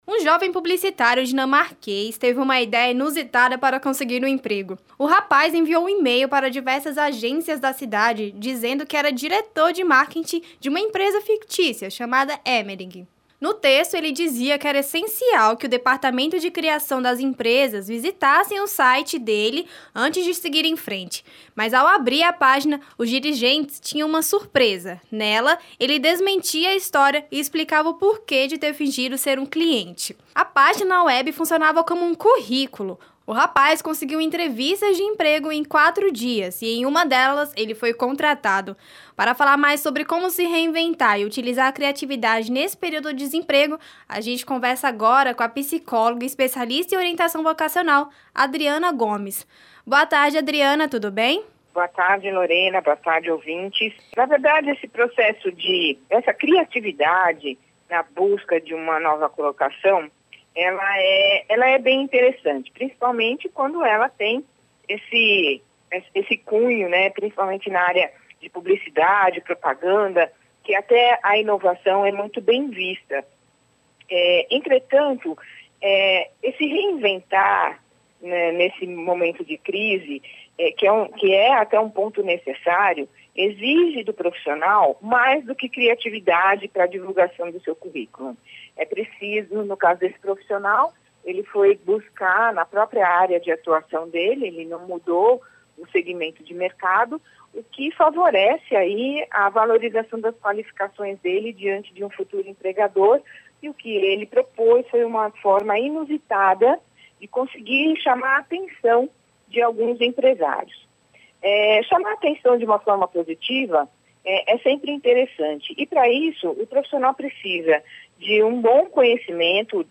Entrevista para Band News FM de Brasília